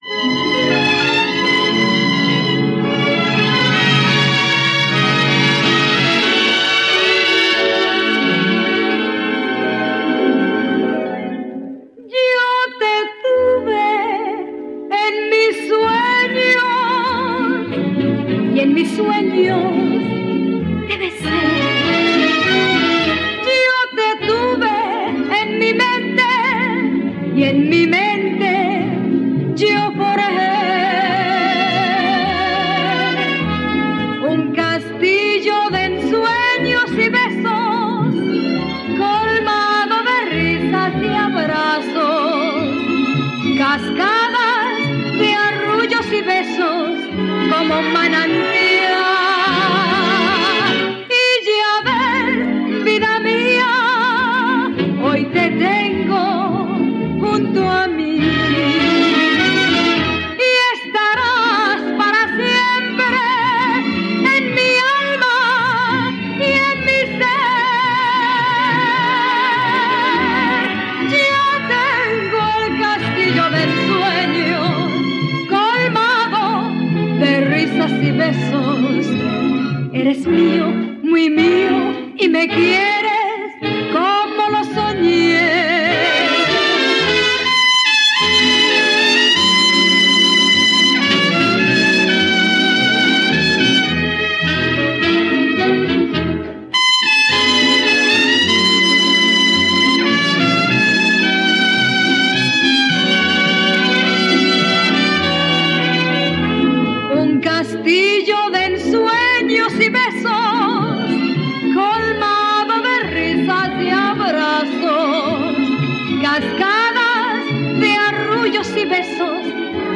Hermosa interpretación.